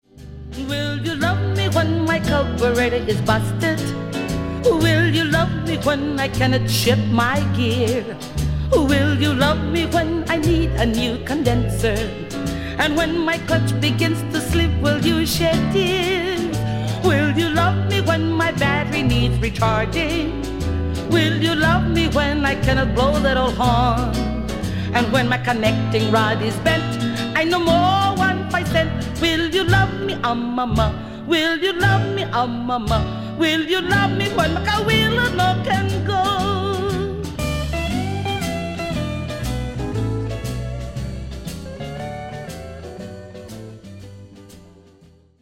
Down memory lane with Hawaiian tunes
• Genre: Contemporary and traditional Hawaiian.